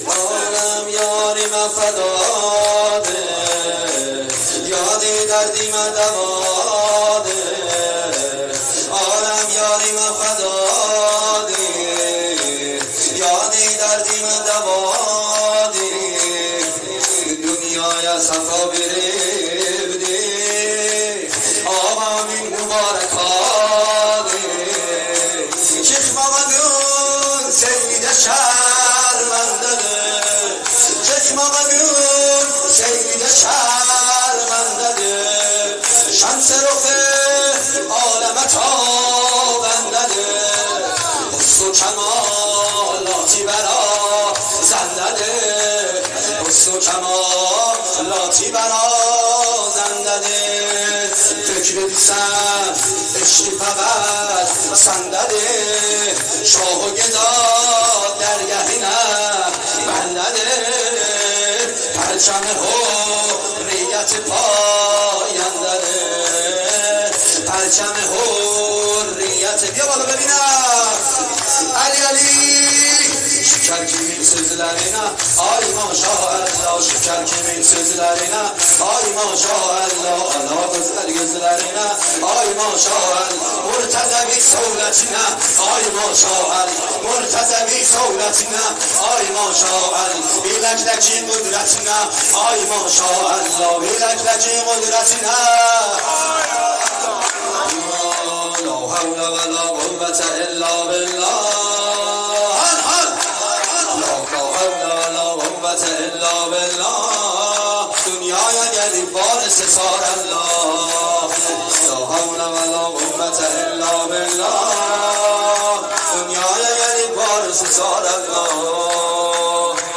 شور _ عالم یارمه فدادی